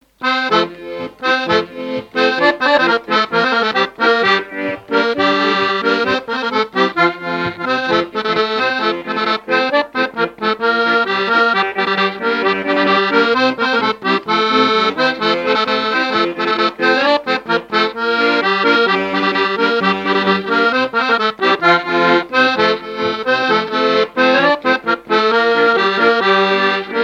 Saint-Christophe-du-Ligneron
Chants brefs - A danser
Pièce musicale inédite